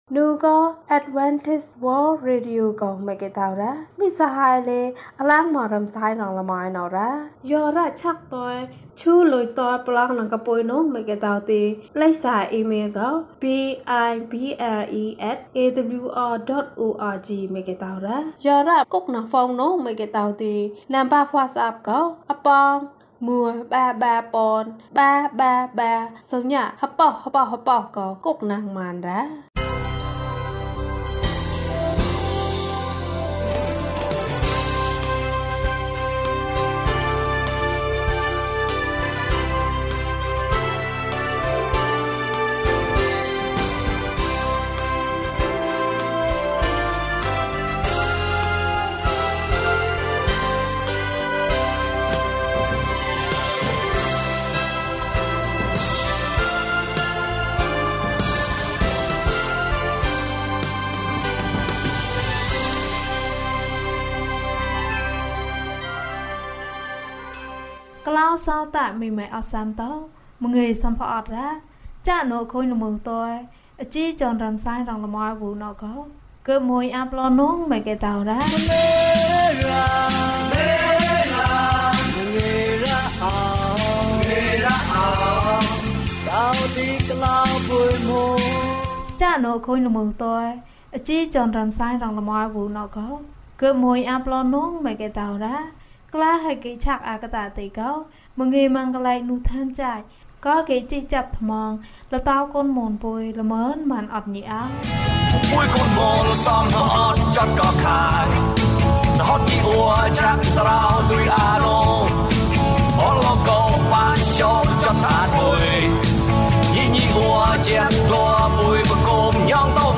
ဘုရားသခင် သင်နှင့်အတူ အချိန်တိုင်း။၀၁ ကျန်းမာခြင်းအကြောင်းအရာ။ ဓမ္မသီချင်း။ တရားဒေသနာ။